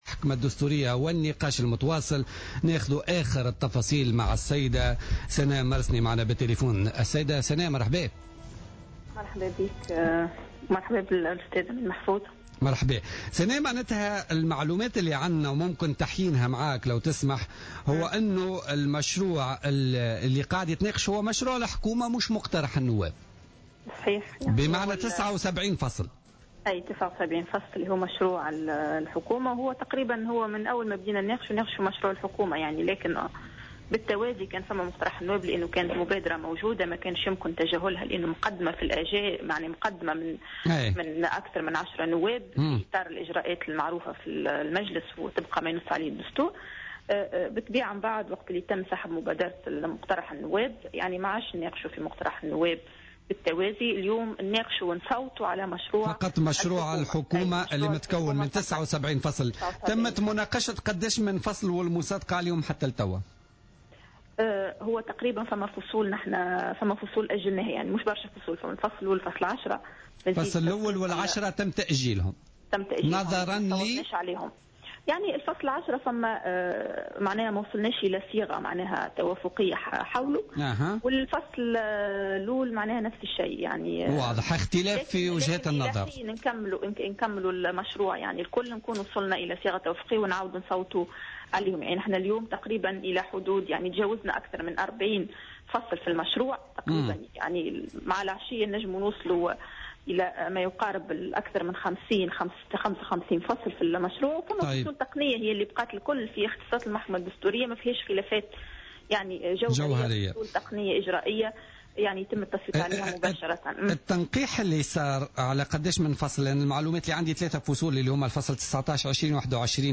أكدت سناء مرسني مقررة لجنة التشريع العام في مداخلة لها في بوليتيكا اليوم...